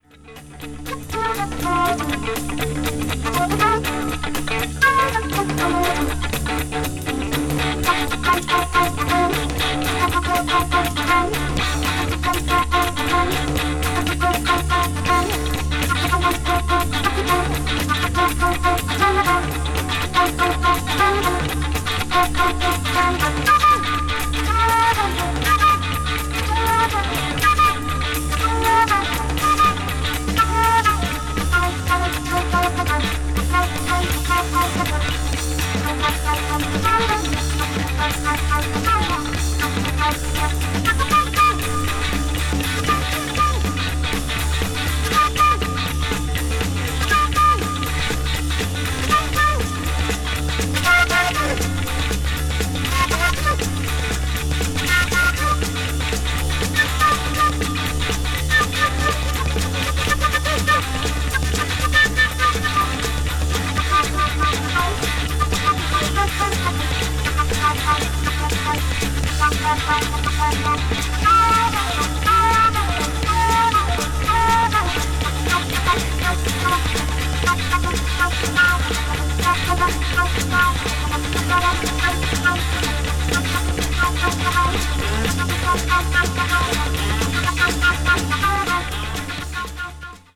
media : EX/EX(わずかにチリノイズが入る箇所あり)
blues rock   jazz rock   progressive rock   psychedelic rock